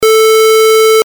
pic 3-8: typical sawtooth, square and noise waveform with corresponding harmonics
sqr440.mp3